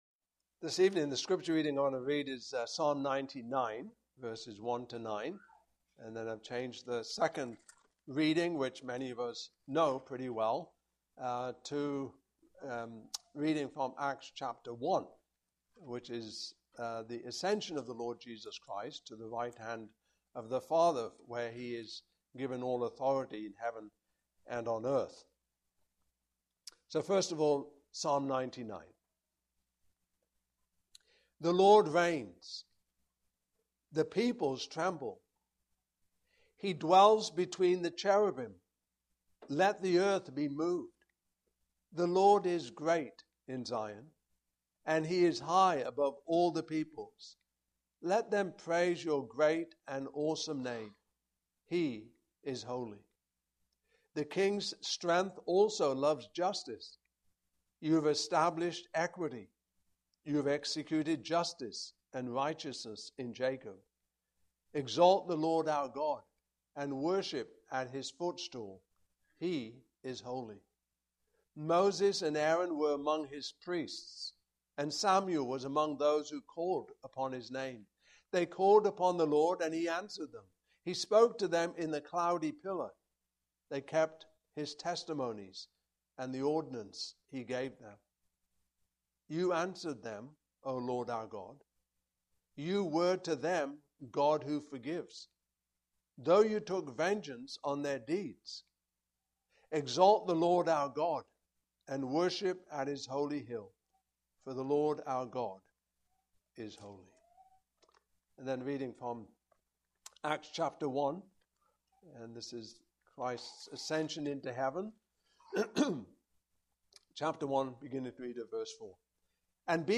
Westminster Shorter Catechism Passage: Psalm 99:1-9, Acts 1:4-12 Service Type: Evening Service Topics